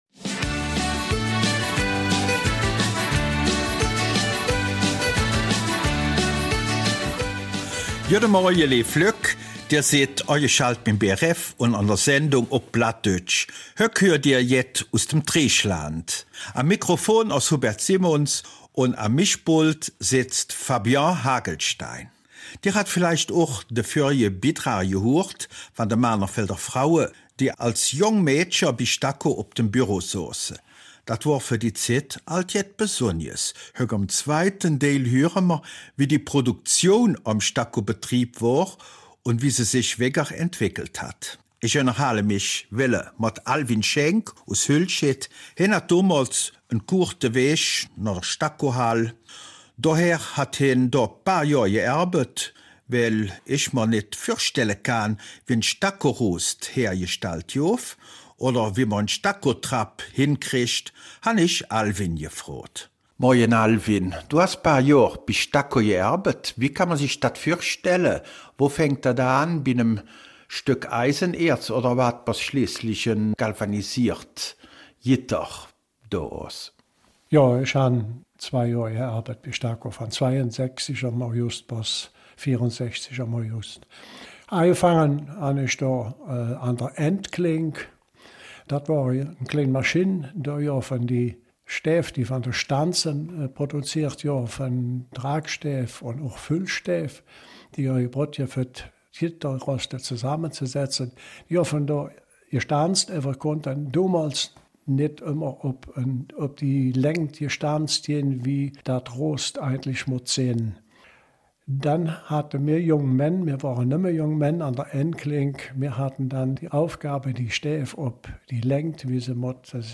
Eifeler Mundart - 22. Februar